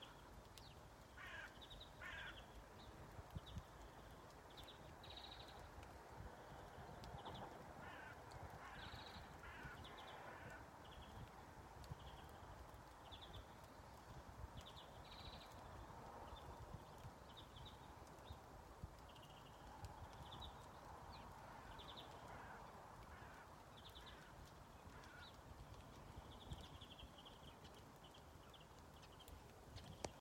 Zaļžubīte, Chloris chloris
StatussDzied ligzdošanai piemērotā biotopā (D)
PiezīmesVai tā ir sarkanrīklīte,kas dzied korītī ar vārnu?